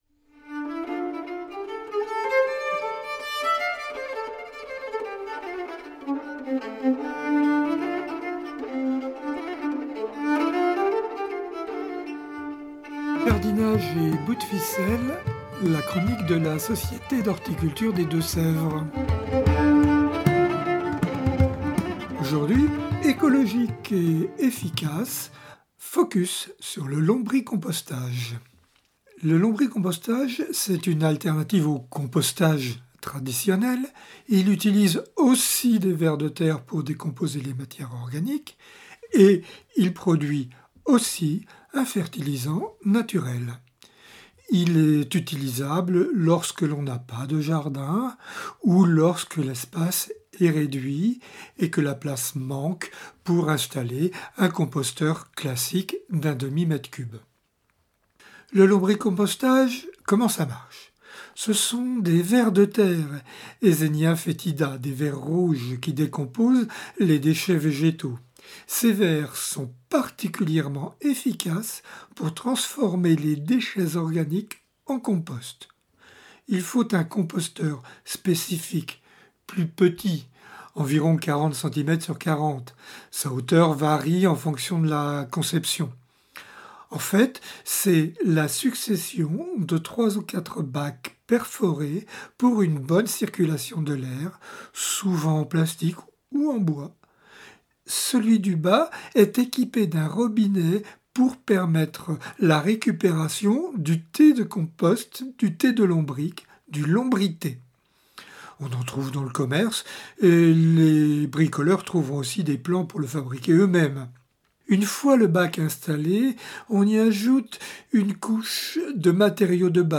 (Ces chroniques sont diffusées chaque semaine sur les radios D4B et Pigouille Radio)